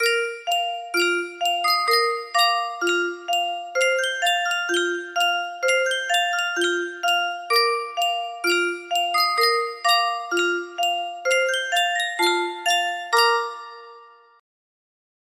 Yunsheng Music Box - Hail Hail The Gang's All Here 6533 music box melody We use cookies to give you the best online experience.
Type Full range 60
BPM 64